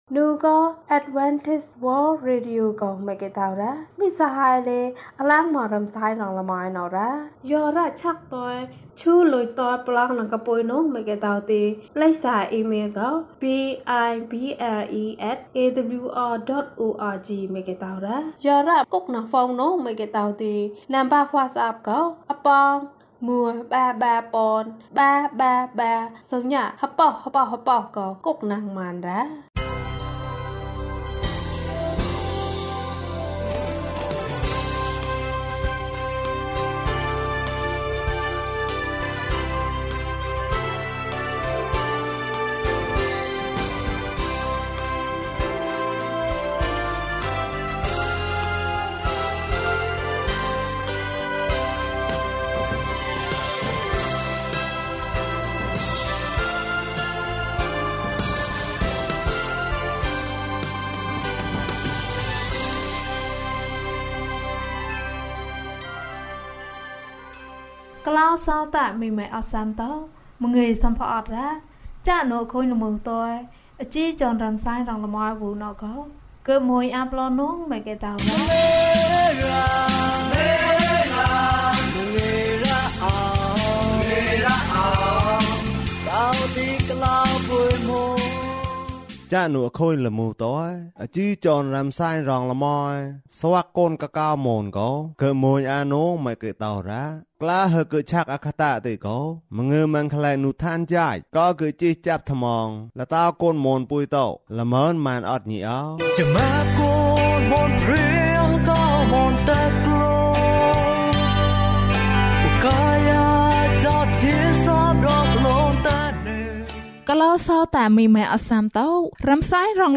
ဘုရားသခင်သည် ချစ်ခြင်းမေတ္တာဖြစ်သည်။၀၅ ကျန်းမာခြင်းအကြောင်းအရာ။ ဓမ္မသီချင်း။ တရားဒေသနာ။